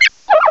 cry_not_patrat.aif